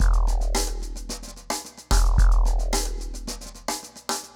RemixedDrums_110BPM_30.wav